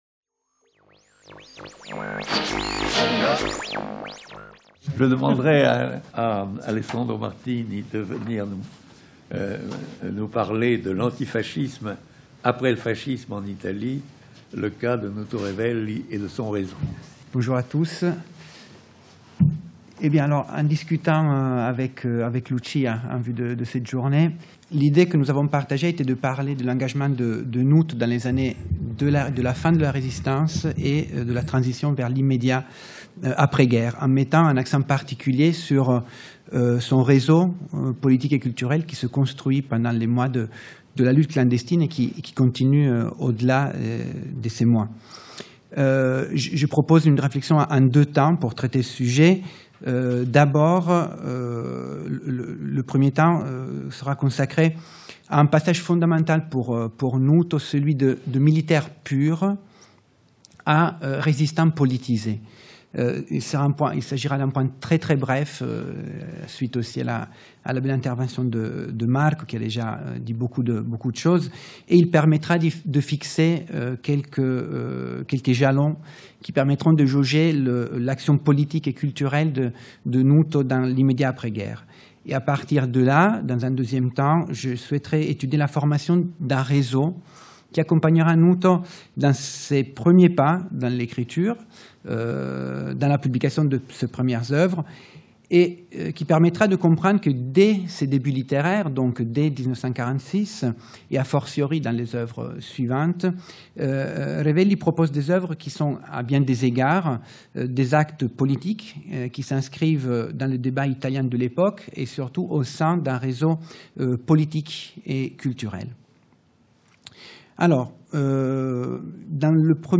Journée d'étude Nuto Revelli 5e partie
Dans le cadre du programme de manifestations à Paris organisé par la Fondation Nuto Revelli à l'occasion du centenaire de Nuto Revelli, la FMSH accueille une journée d'étude autour de Nuto Revelli, écrivain italien du XXe siècle et l'une des figures majeures de la Résistance italienne pendant la Seconde Guerre mondiale.